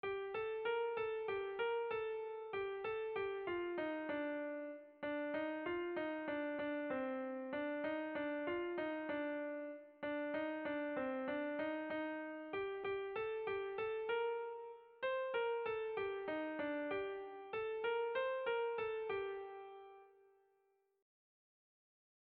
ABDE